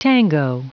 Prononciation du mot tango en anglais (fichier audio)
Prononciation du mot : tango